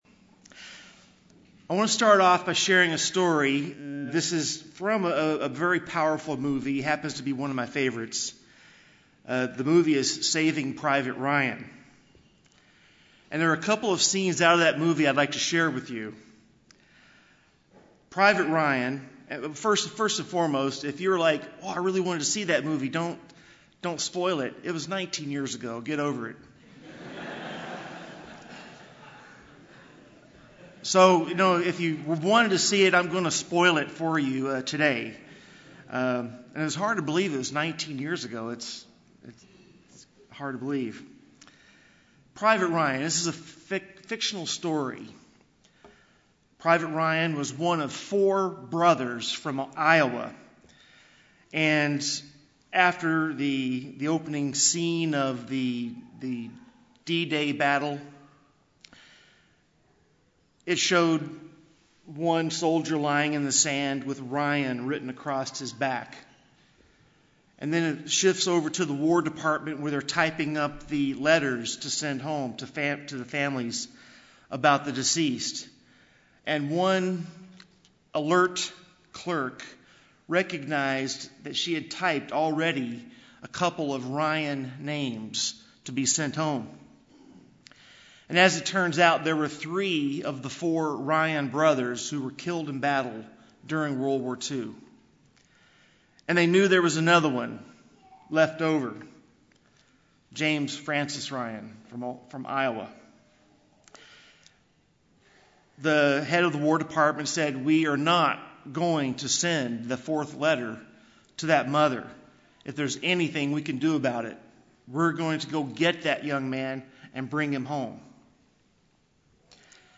This message, given on the first Day of Unleavened Bread, focuses on the spiritual lessons derived from this season. As we eliminate leaven from our physical surroundings and partake of unleavened bread, we discern the spiritual meaning of striving to eliminate pride and sinfulness from our lives and developing a godly attitude of humility and love toward others.